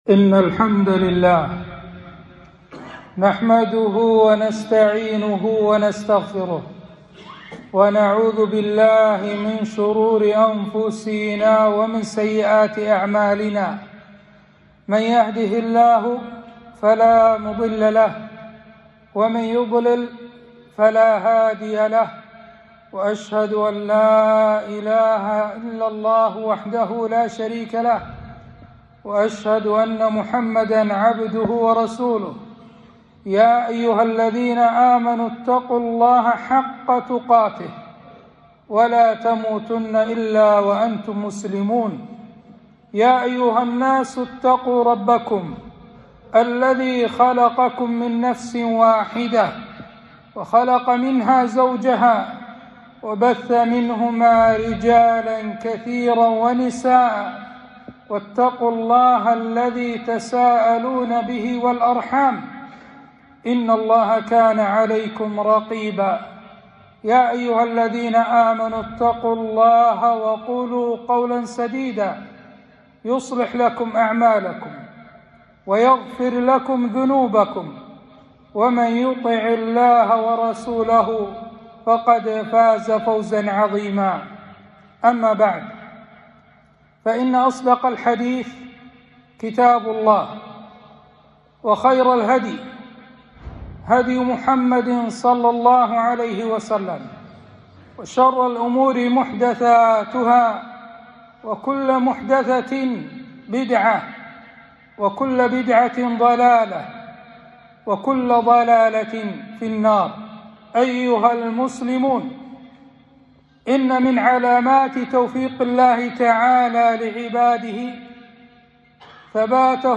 خطبة - فالله خير حافظا وهو أرحم الراحمين